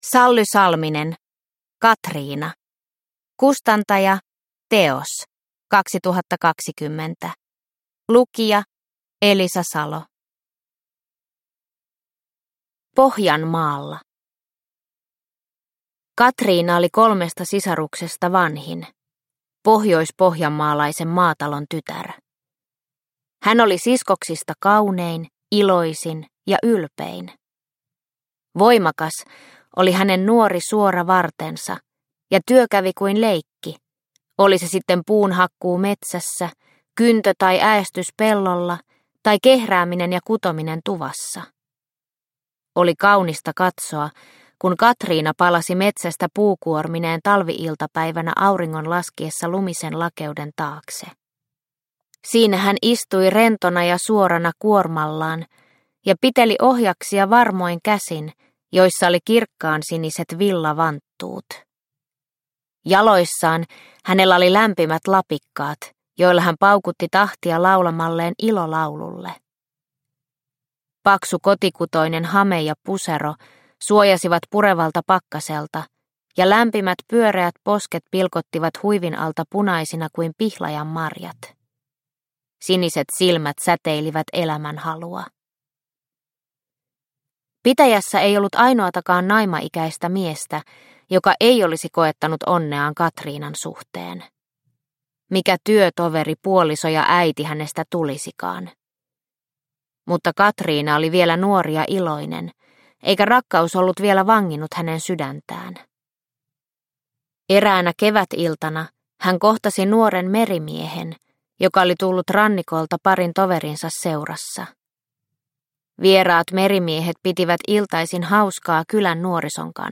Katrina – Ljudbok – Laddas ner